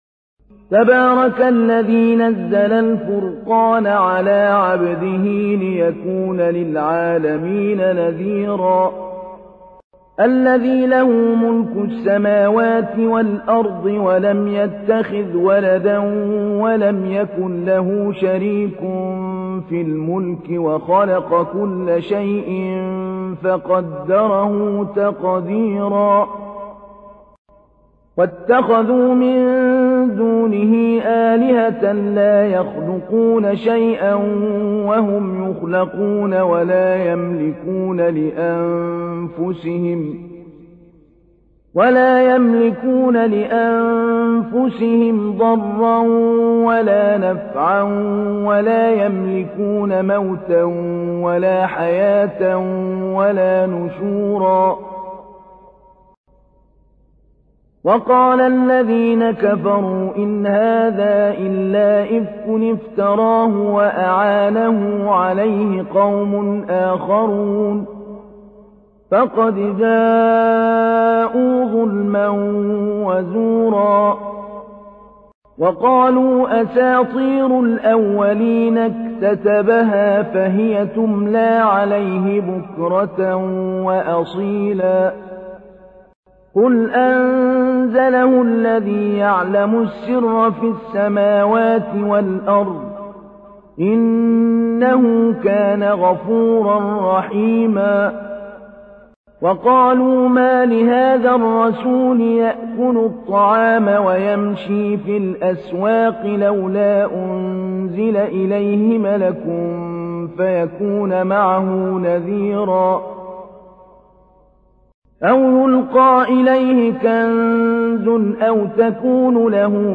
تحميل : 25. سورة الفرقان / القارئ محمود علي البنا / القرآن الكريم / موقع يا حسين